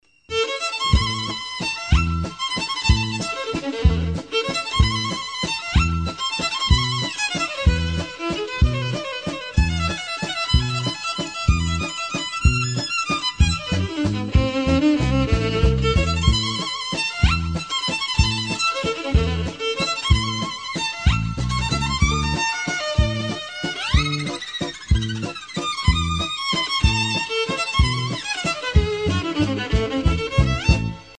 Классическая скрипка